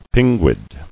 [pin·guid]